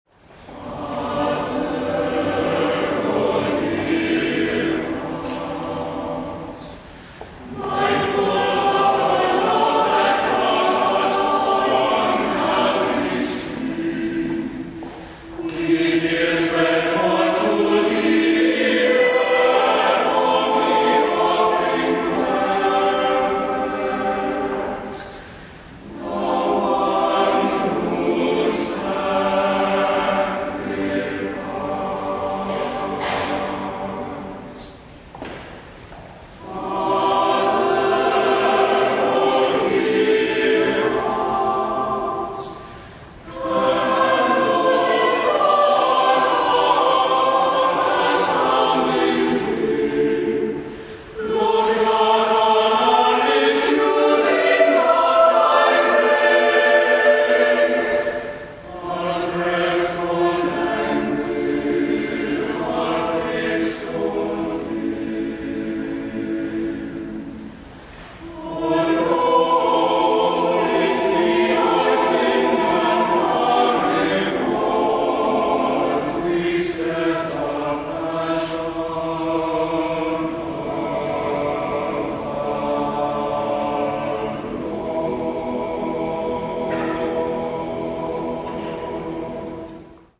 Genre-Style-Form: Sacred ; Anthem ; Prayer
Type of Choir: SATB  (4 mixed voices )
Tonality: G major